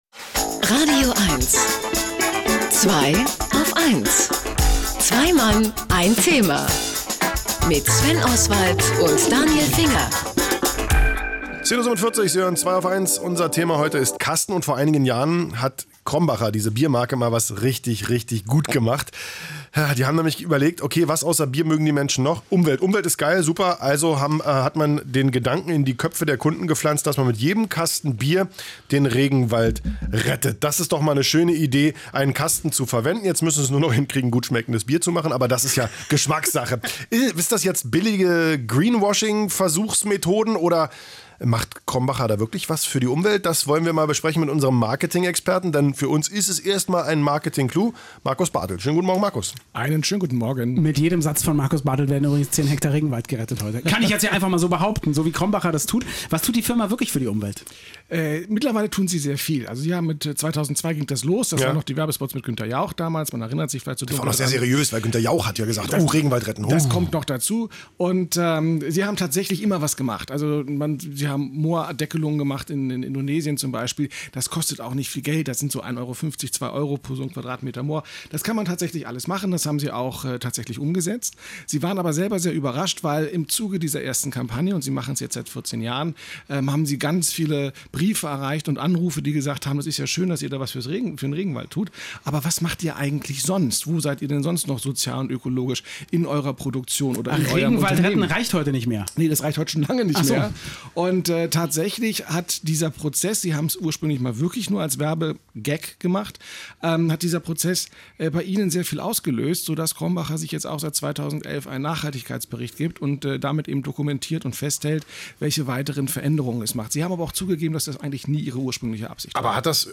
Und Anlaß genug für die Herren von „Zweiaufeins„, mich zum Thema „Kasten“ in das radioeins-Studio einzuladen, um über Sinn und Unsinn solcher Aktionen zu sprechen.